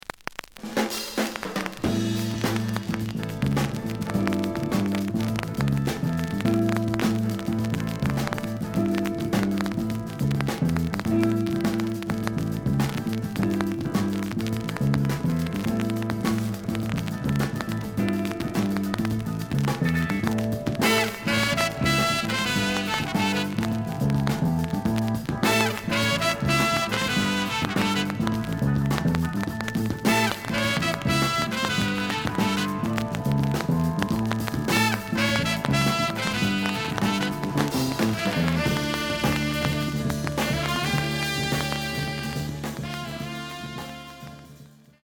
The audio sample is recorded from the actual item.
●Format: 7 inch
●Genre: Funk, 70's Funk
Some noise on B side.)